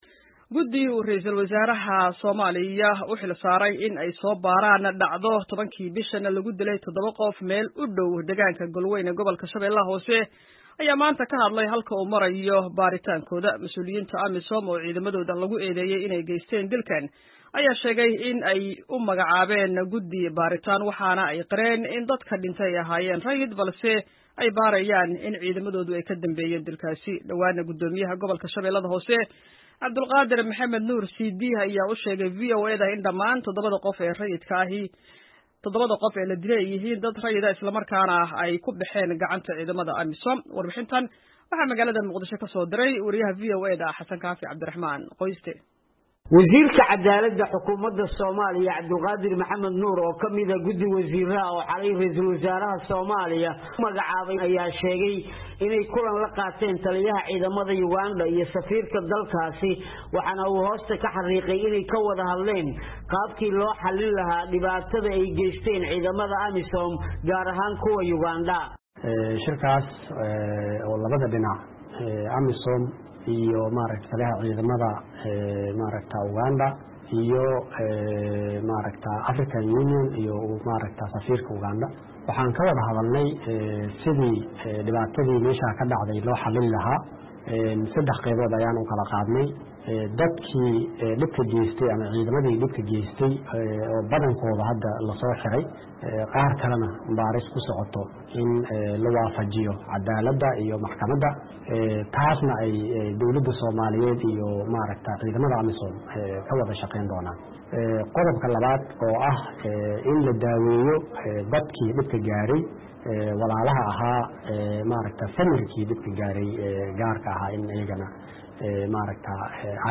MUQDISHO —